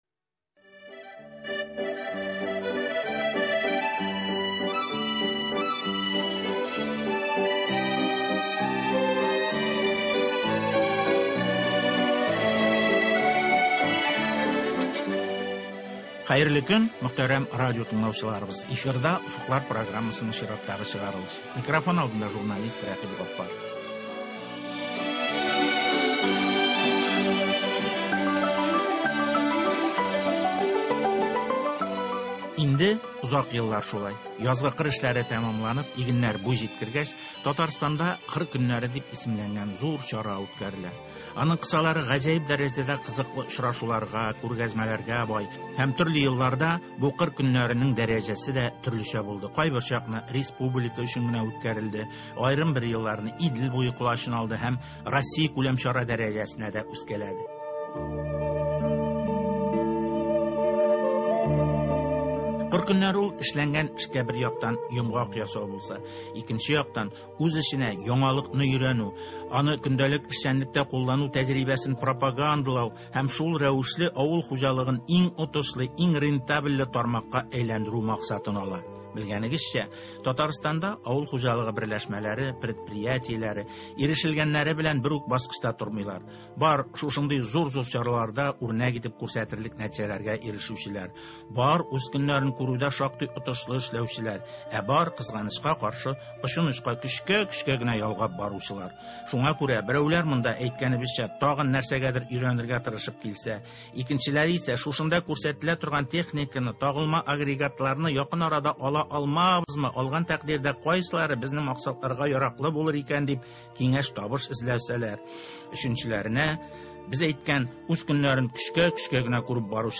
әңгәмә